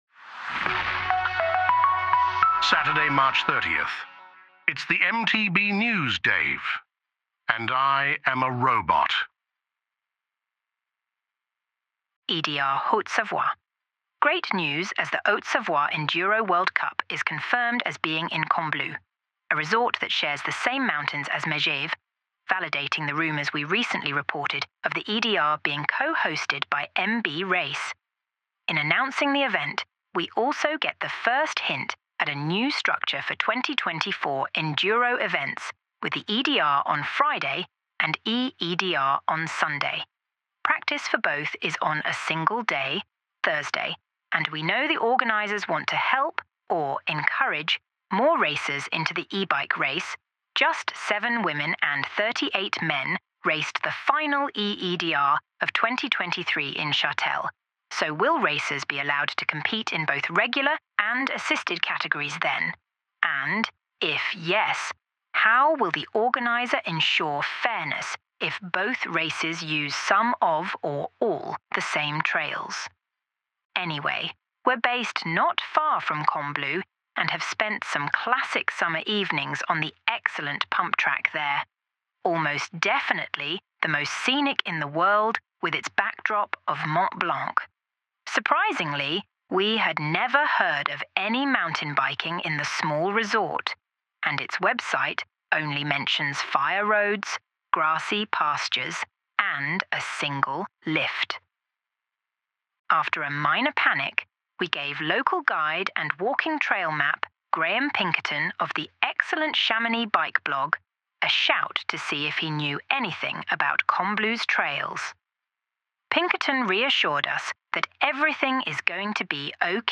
*Dave is computer generated